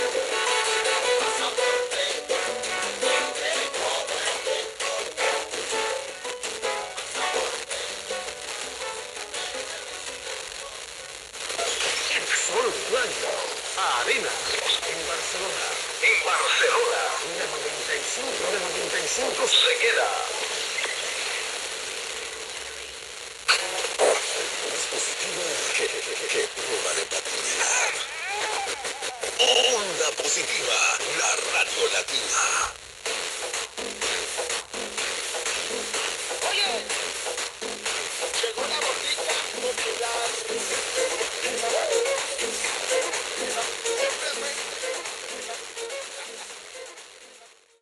Música, publicitat, indicatiu de l'emissora i tema musical
Qualitat de so defectuosa